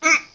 Player_Hurt 01.wav